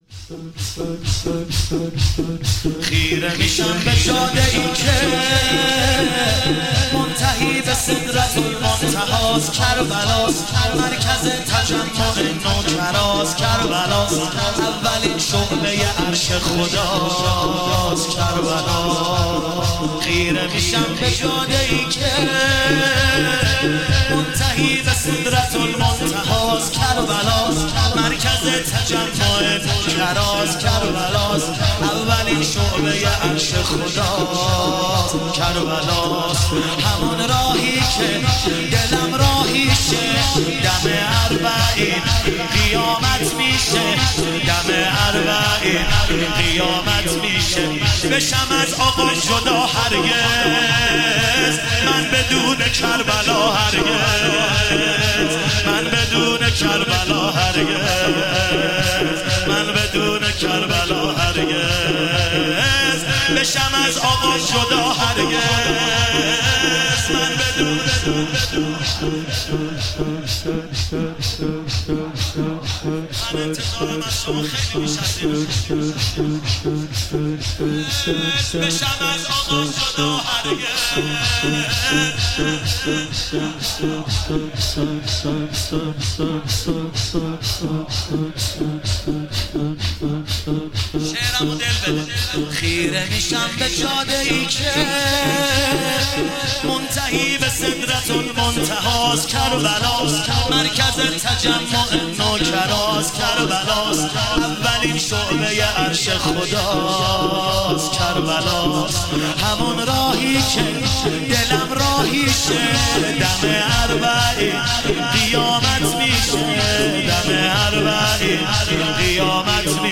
مناسبت : شب اول محرم
قالب : شور